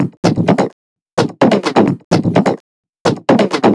VEH1 Fx Loops 128 BPM
VEH1 FX Loop - 19.wav